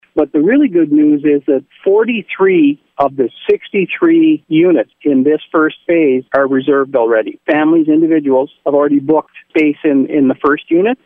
That’s Reeve Peter Emon, he says this could potentially be the beginning of some serious growth in the town of Renfrew.